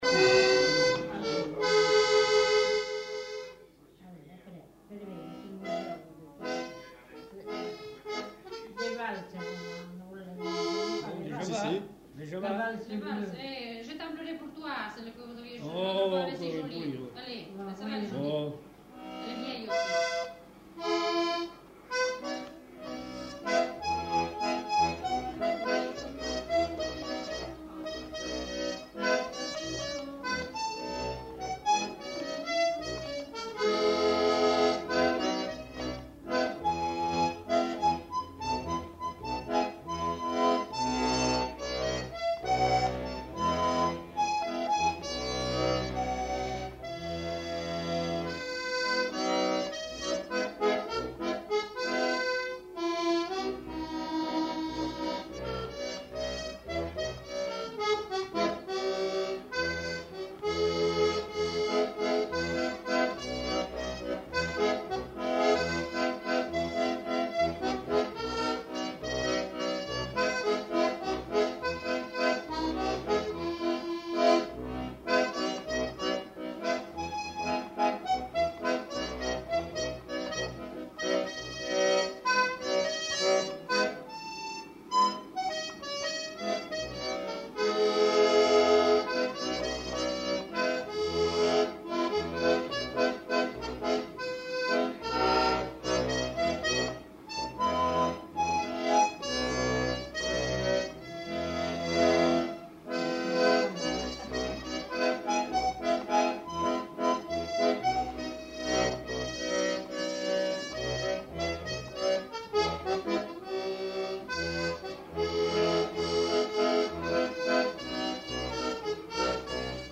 Aire culturelle : Savès
Lieu : Pavie
Genre : morceau instrumental
Instrument de musique : accordéon diatonique
Danse : valse